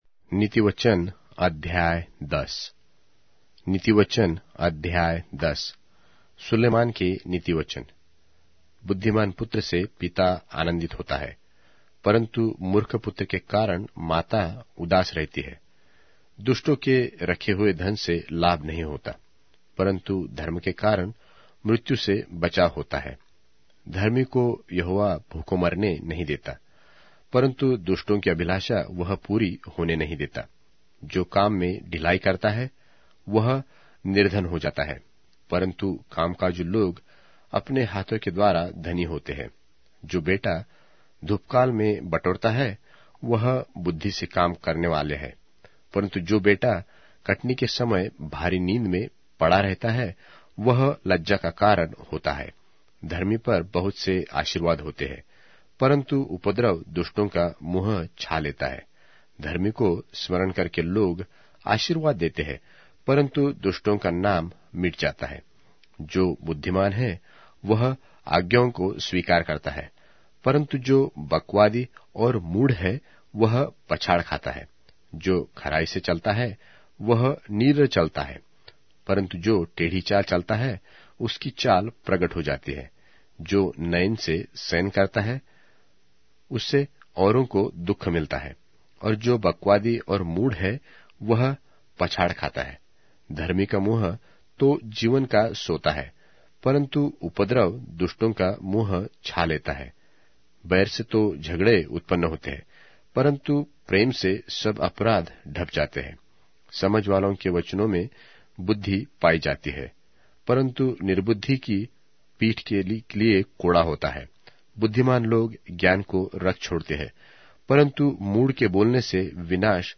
Hindi Audio Bible - Proverbs 9 in Guv bible version